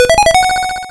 RedCoin1.wav